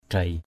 /d̪reɪ/